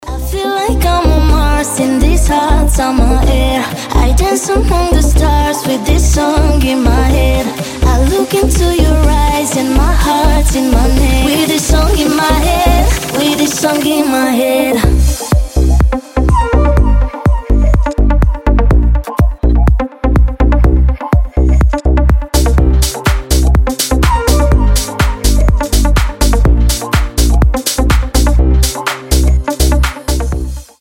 deep house
летние